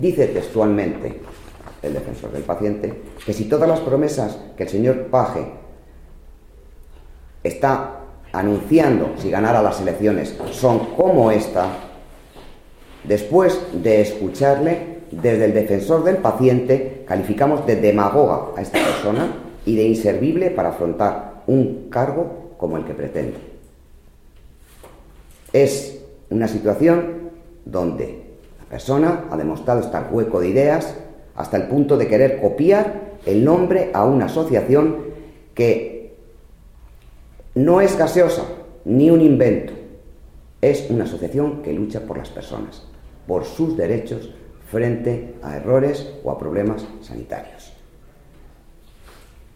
Durante una rueda de prensa, Agudo ha exhibido un documento en el que se recoge el malestar del Defensor del Paciente por las manifestaciones de Page diciendo que, si gana las elecciones en mayo, iba a crear la figura del Defensor del Paciente cuando es esta figura ya existe.